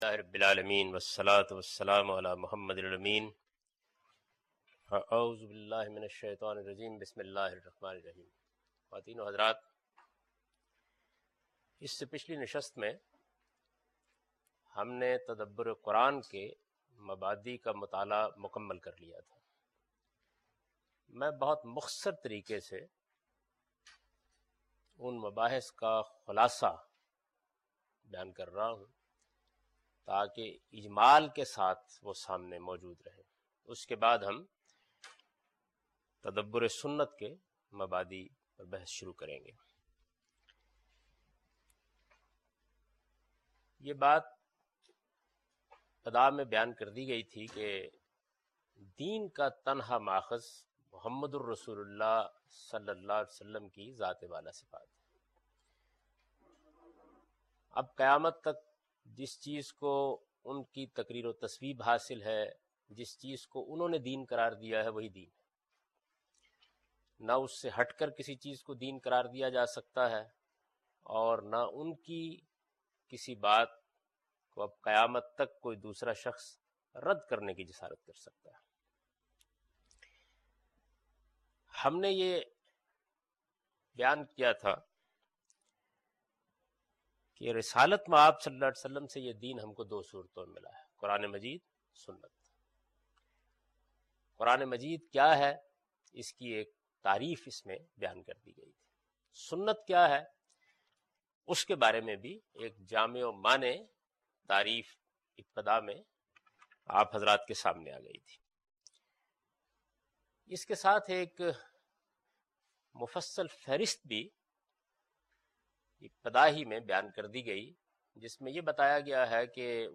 A comprehensive course on Islam, wherein Javed Ahmad Ghamidi teaches his book ‘Meezan’.
In this lecture series, he not only presents his interpretation of these sources, but compares and contrasts his opinions with other major schools developed over the past 1400 years. In this lecture he introduces fundamental principles to understand Sunnah.